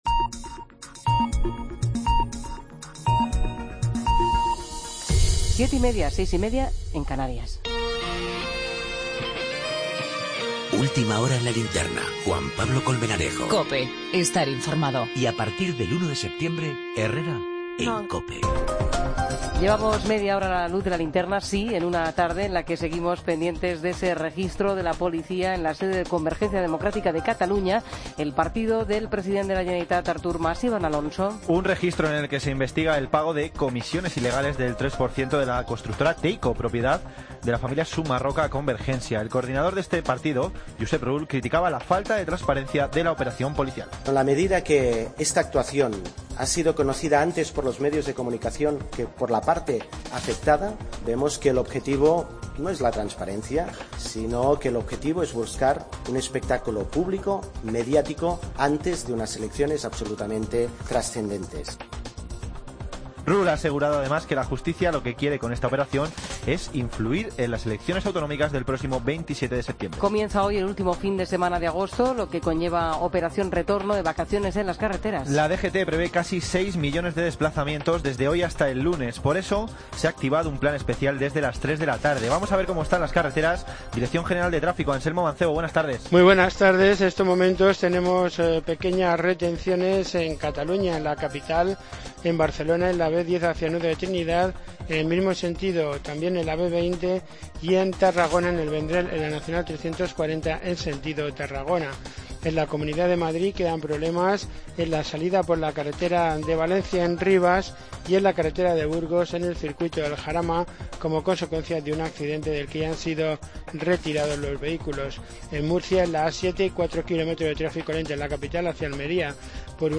Ronda de corresponsales.